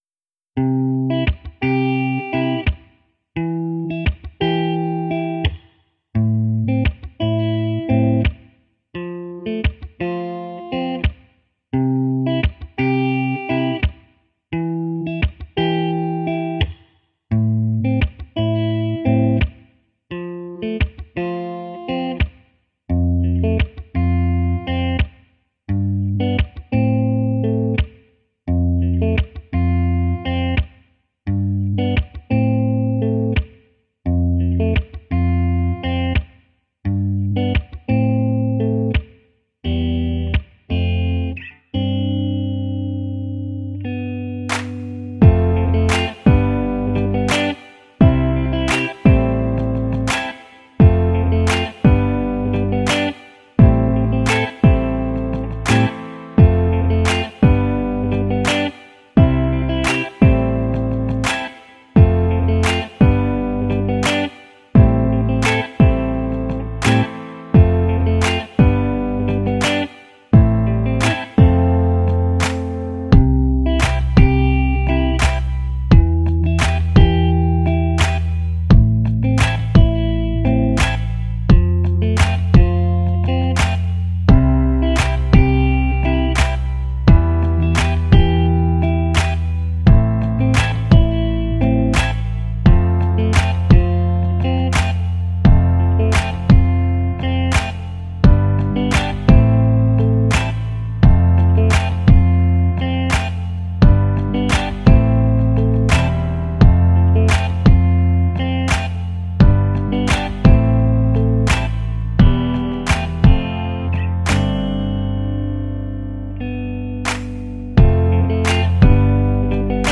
Karaoke Version